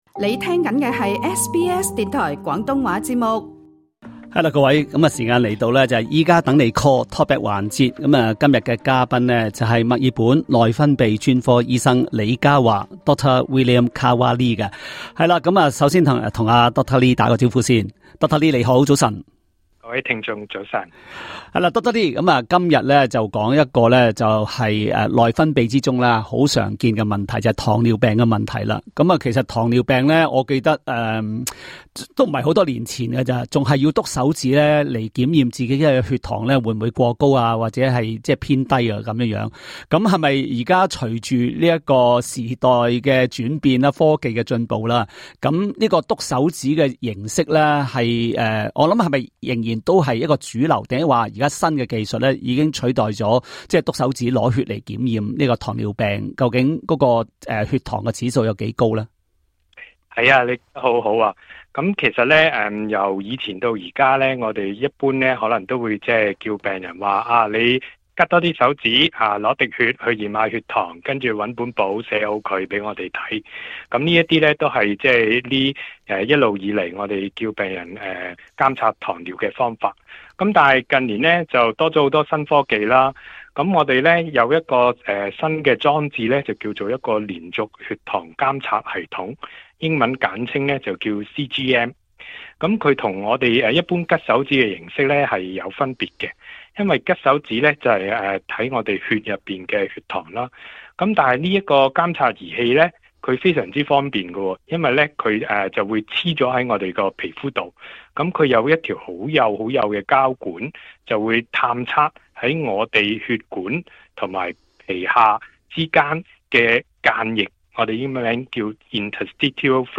另外，他也解答聽眾的查詢。